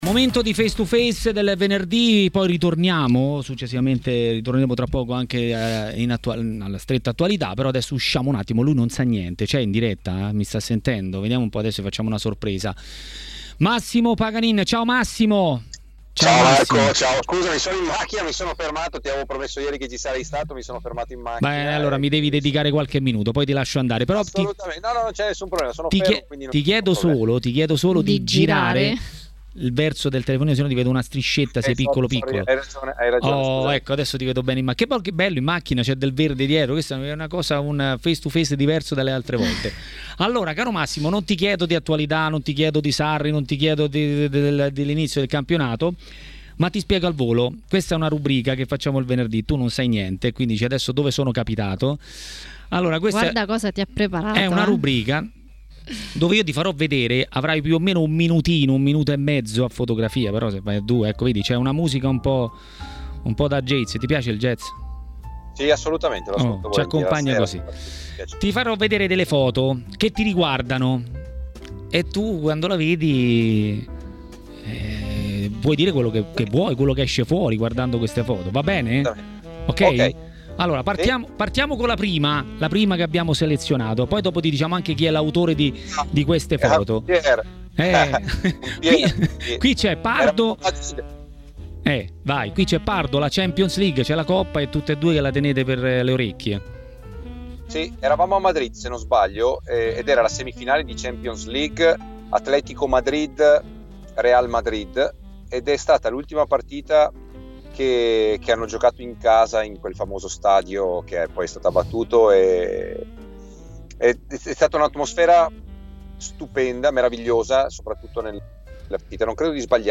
Massimo Paganin è il protagonista del nuovo face-to-face di Maracanà, nel pomeriggio di TMW Radio.